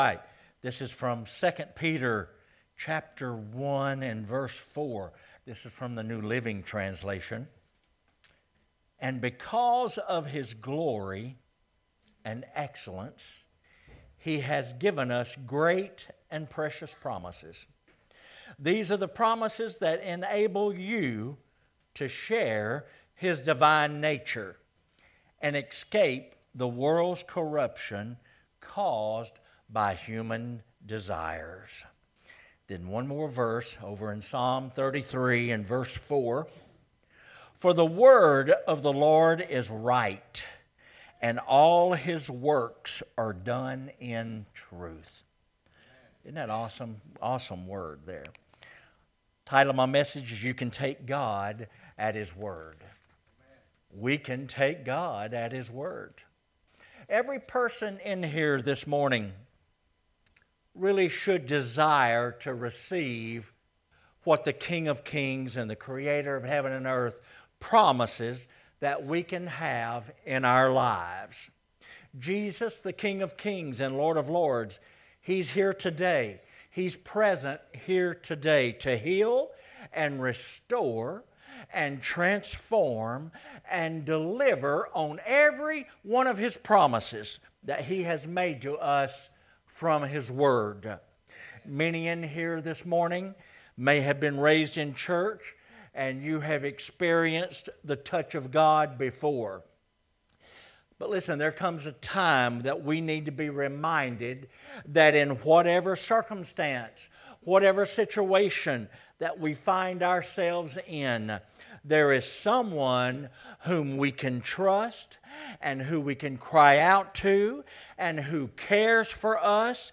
Sermons | New Life Ministries